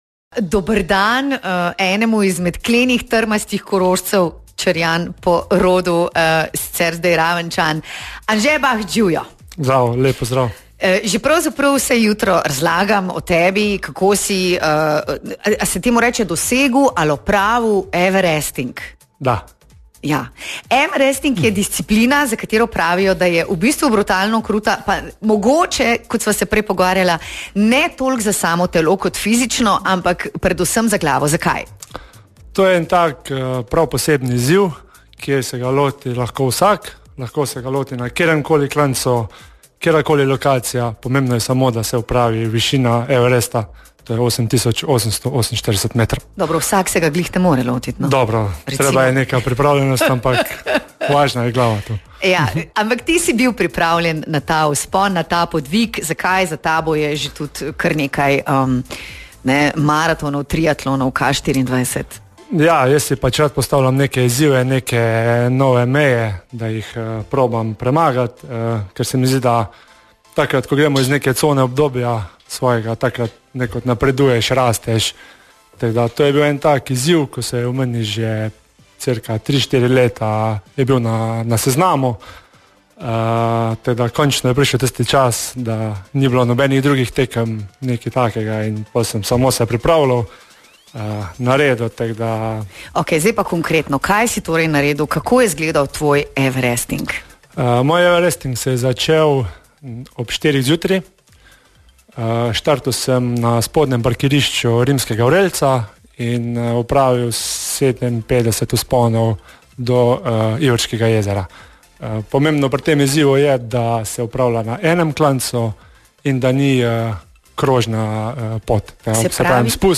Več je povedal sam v našem studiu: